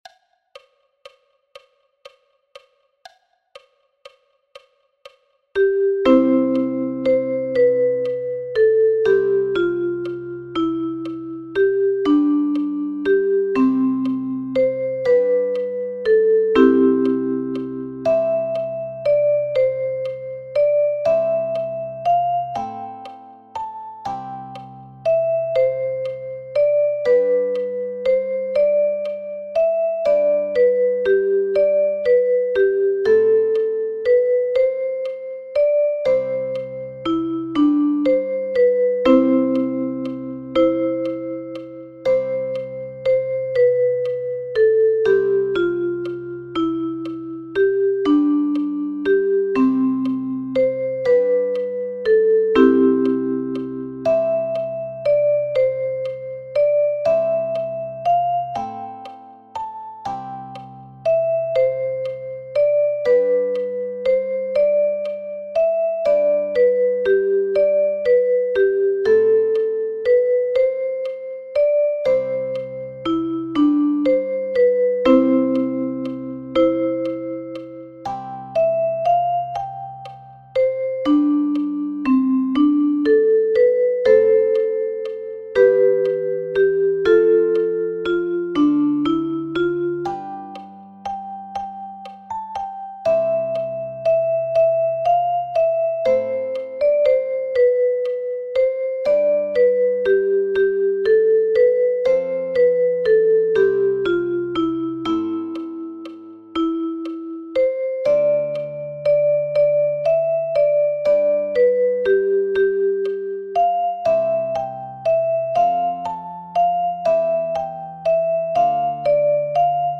notiert für das Flatpicking mit der Ukulele..